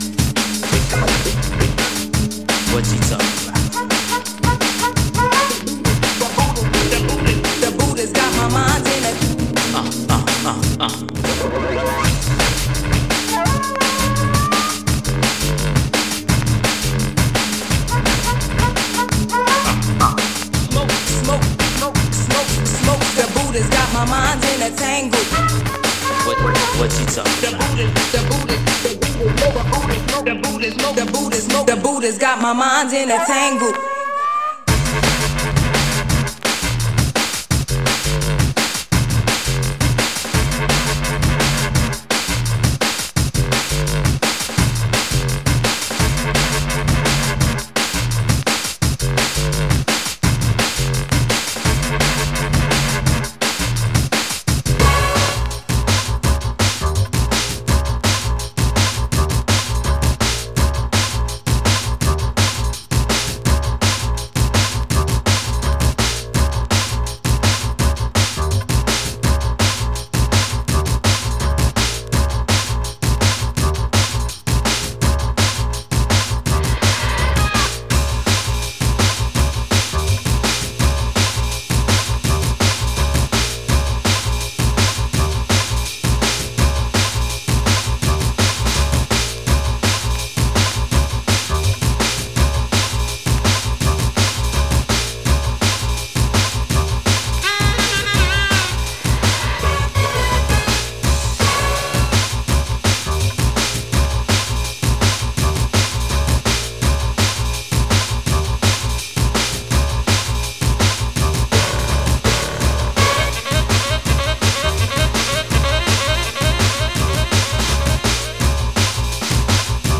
Surface marks cause some light noise on playback